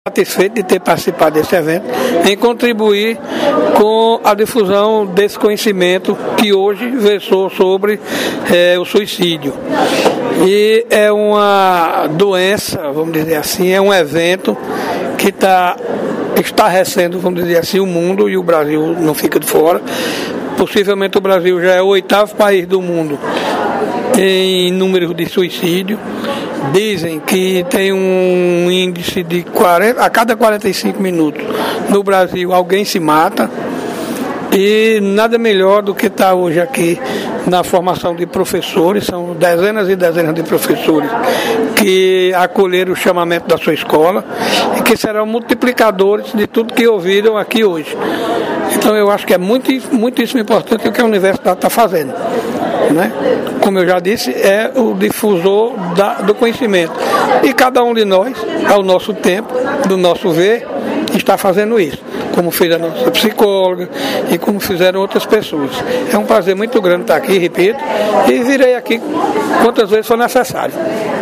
Abaixo as entrevistas completas: